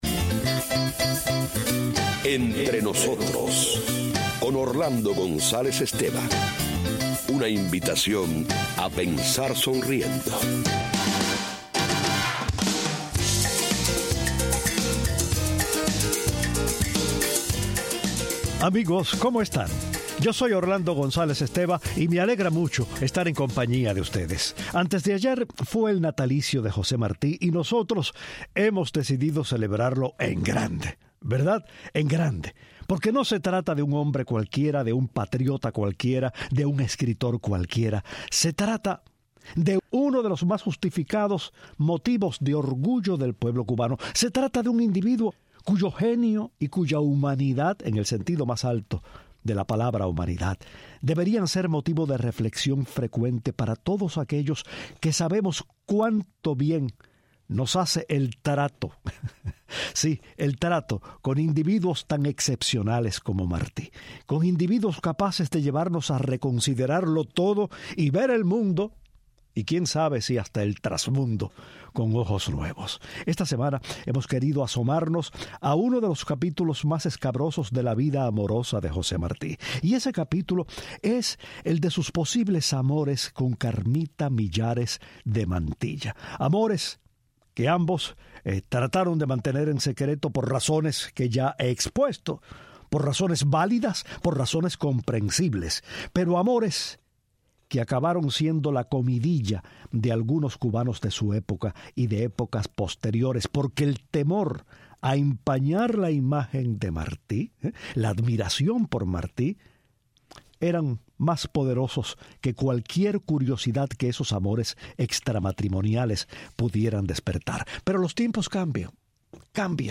lee unas cartas de la viuda y el hijo de Martí escritas después de su muerte